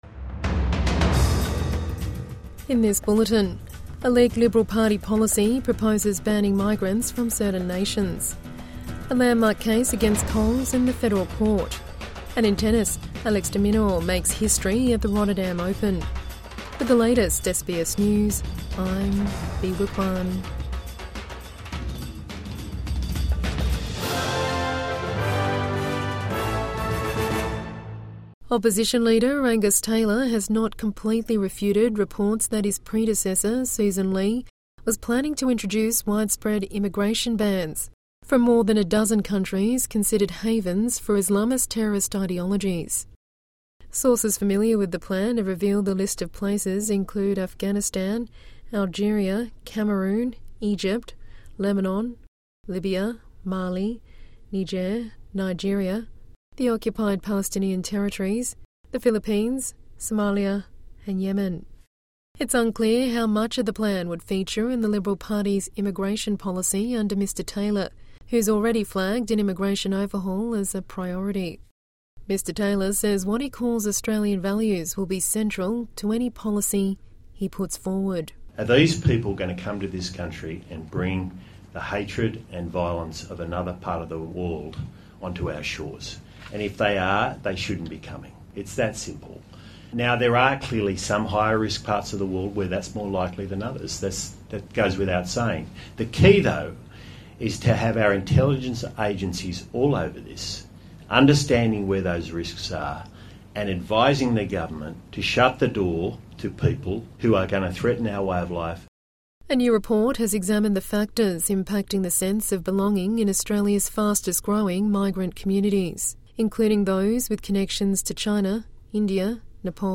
Listen to Australian and world news, and follow trending topics with SBS News Podcasts.